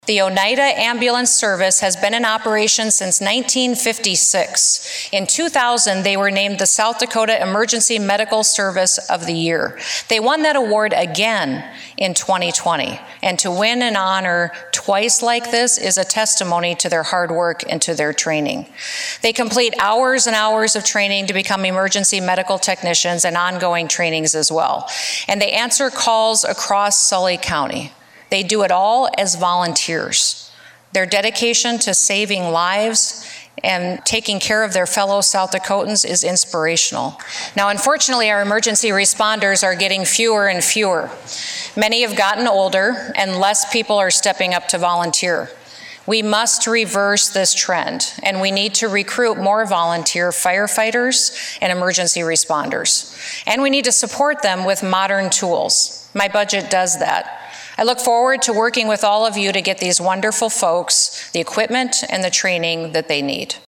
In her State of the State Address this week (Jan. 11, 2022), Governor Kristi Noem highlighted the work of the Onida Volunteer Ambulance Service.